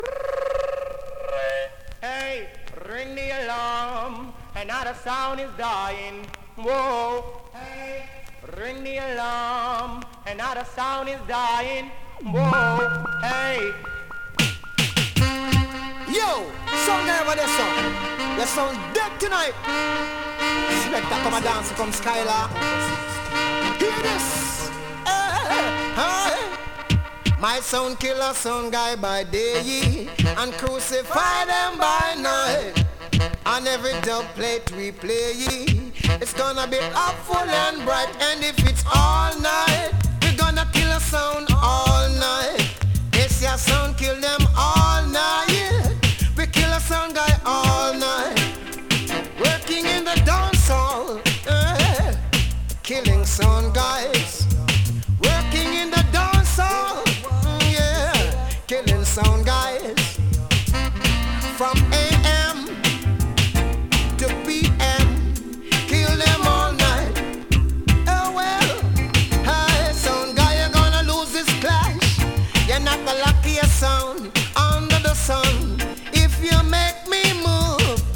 DANCEHALL!!
スリキズ、ノイズ比較的少なめで